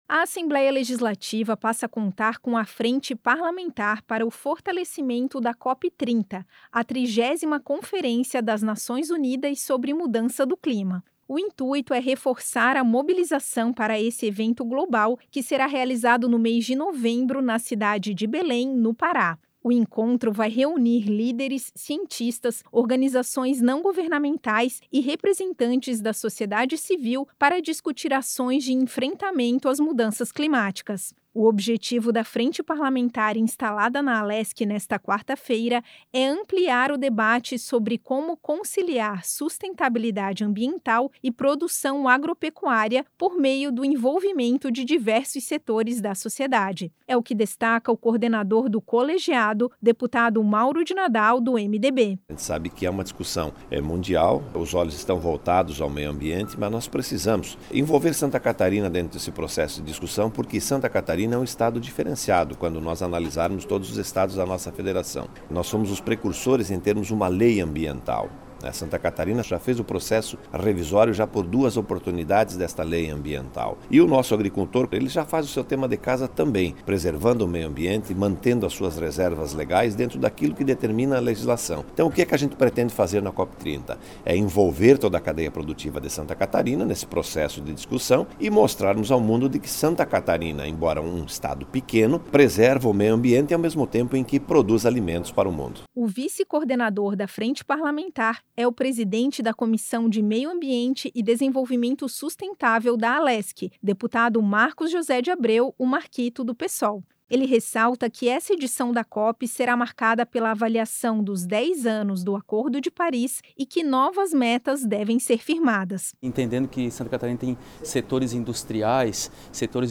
Entrevistas com:
- deputado Mauro de Nadal (MDB), coordenador da Frente Parlamentar para o Fortalecimento da COP 30;